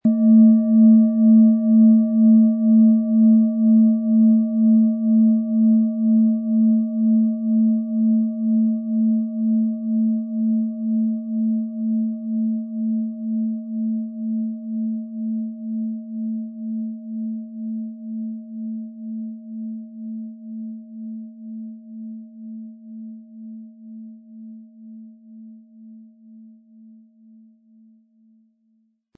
Venus
Planetenschale®
• Mittlerer Ton: Wasserstoffgamma
Unter dem Artikel-Bild finden Sie den Original-Klang dieser Schale im Audio-Player - Jetzt reinhören.
HerstellungIn Handarbeit getrieben
MaterialBronze